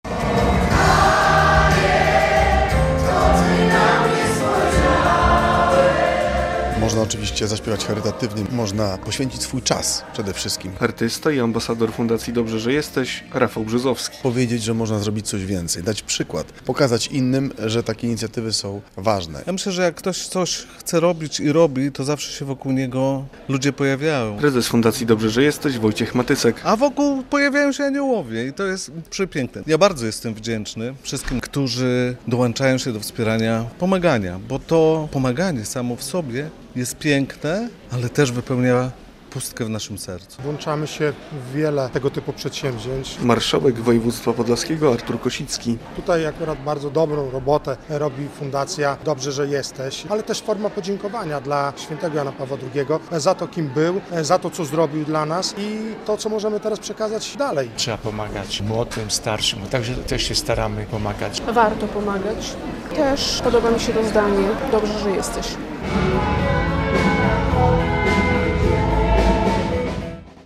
W środę (17.05) w Operze i Filharmonii Podlaskiej podczas uroczystej gali dobroczynności uczczono pamięć Papieża Polaka w przeddzień 103. rocznicy urodzin św. Jana Pawła II. Odbyły się także koncerty, świadectwa wiary ludzi show-biznesu oraz licytacje wspierające białostocką fundację "Dobrze, że jesteś".